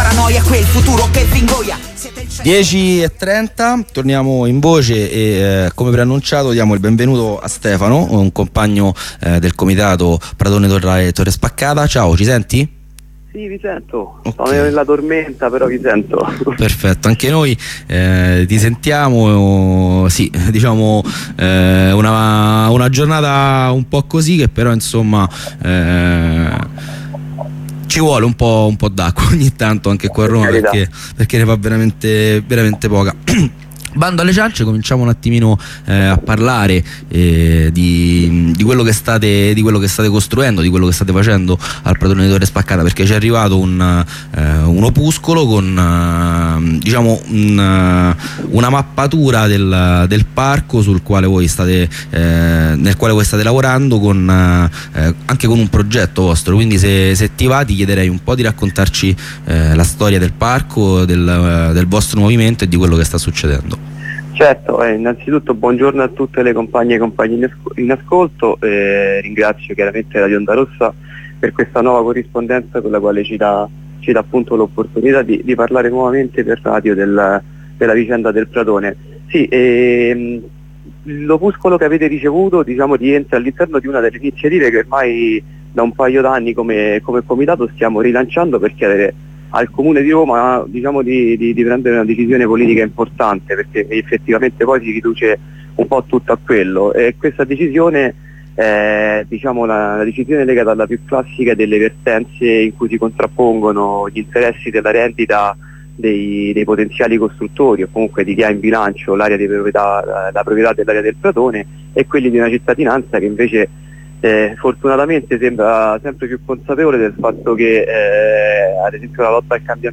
Dal corteo verso il Campidoglio